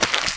STEPS Newspaper, Walk 07.wav